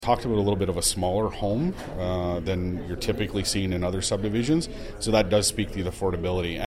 Carr tells Quinte News there’s flexibility in the zoning application and smaller homes are being discussed.